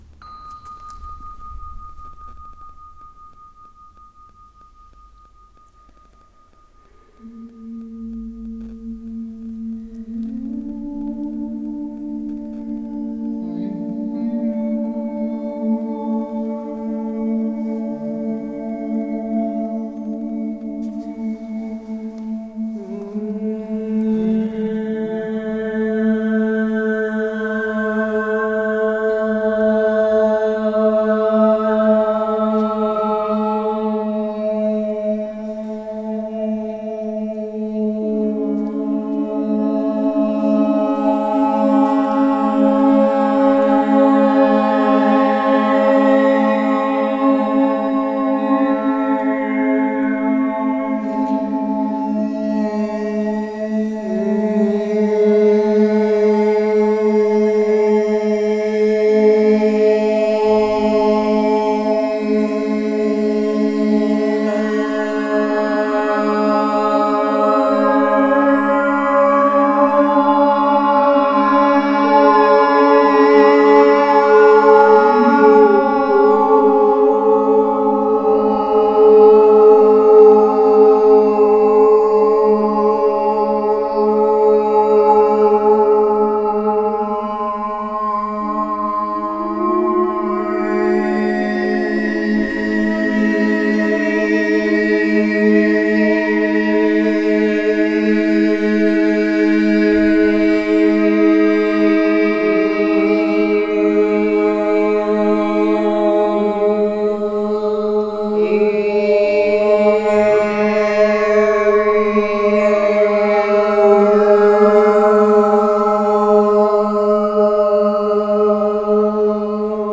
Je vous partage notre récréation dans l’Église de Champniers.